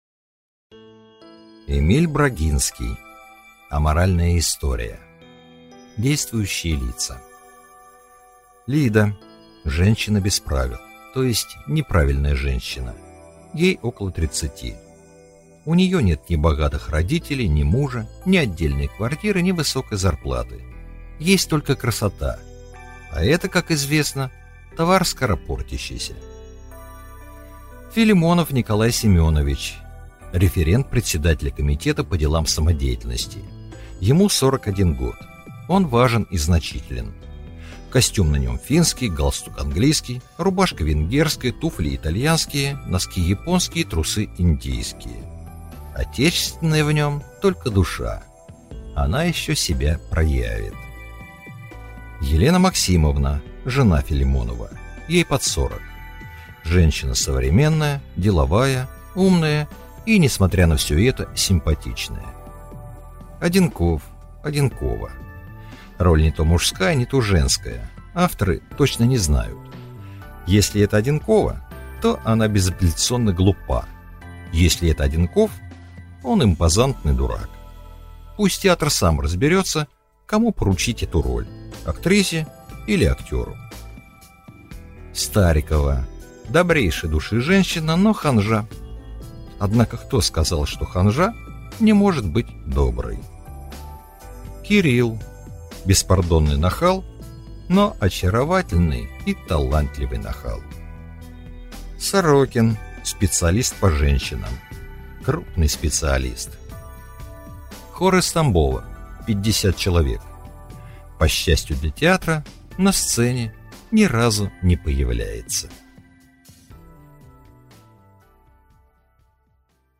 Аудиокнига Аморальная история | Библиотека аудиокниг
Прослушать и бесплатно скачать фрагмент аудиокниги